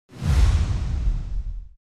Download Free Spaceship Sound Effects | Gfx Sounds
Spacecraft-futuristic-vehicle-approach-fast-2.mp3